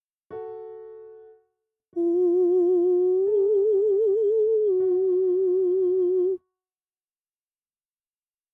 音量注意！
音量と音高を下げて、音数を減らし、持続時間も短くするとこうなりました。
training-strength-falsetto-02.mp3